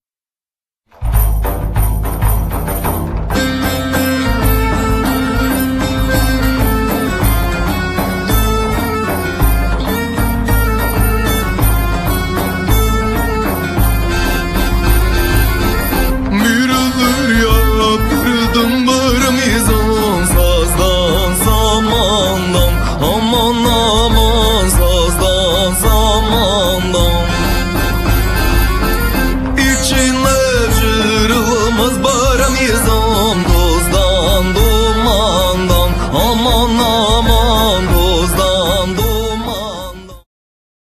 akordeon, śpiew, instrumenty perkusyjne
darabuka, bendir
śpiew, buzuki, tambura, instrumenty perkusyjne
flety
Nagranie: TR Studio, Warszawa; czerwiec 2005